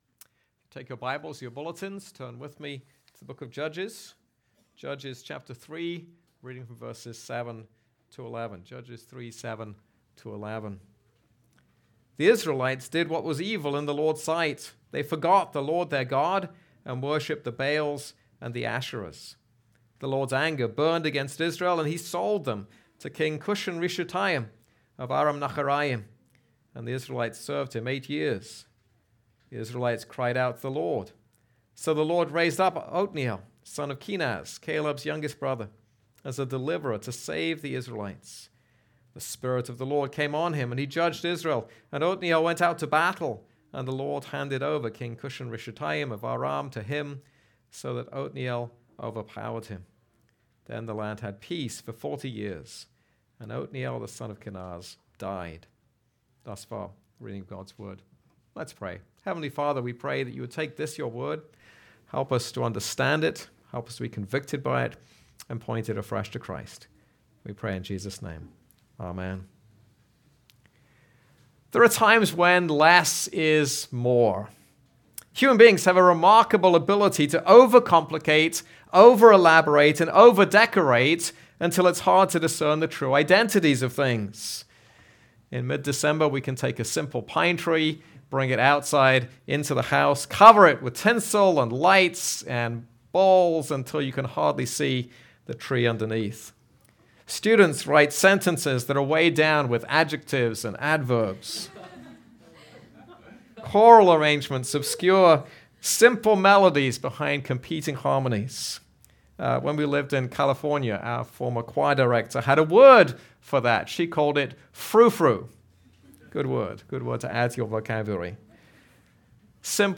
This is a sermon on Judges 3:7-11.